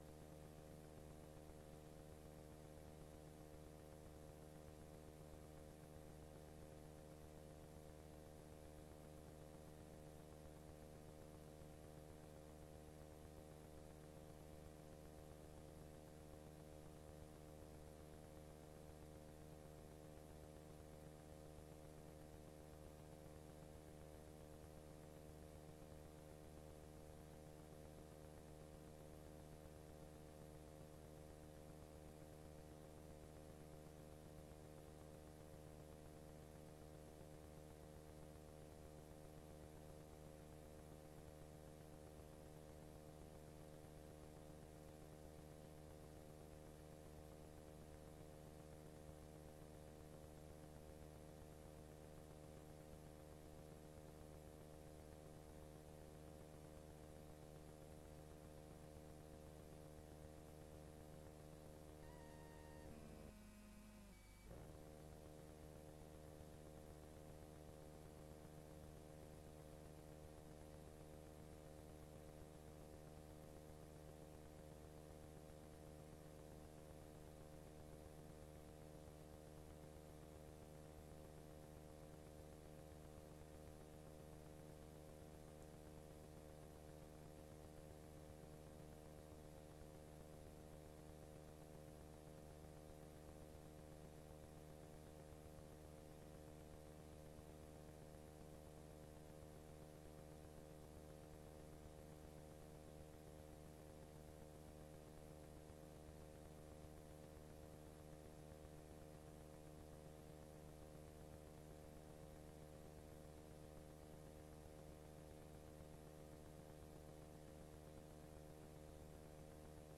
Raadsvergadering 06 mei 2025 20:00:00, Gemeente Huizen
Download de volledige audio van deze vergadering
Locatie: Raadzaal